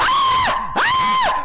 yelling-1